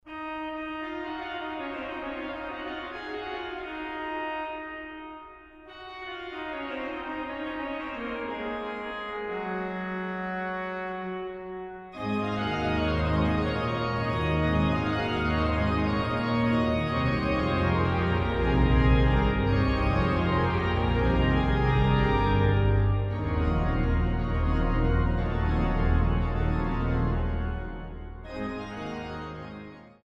Kaps-Orgel in Mariä Himmelfahrt zu Dachau